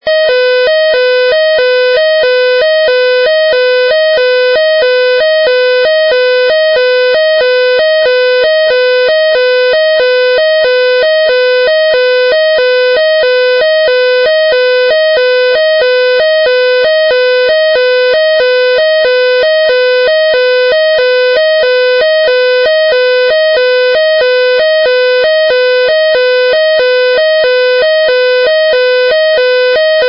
警钟声由电子电路生成，声音标准、无噪声 。
快节拍警报